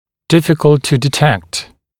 [‘dɪfɪk(ə)lt tə dɪ’tekt][‘дифик(э)лт ту ди’тэкт]трудно обнаружить, трудно диагностировать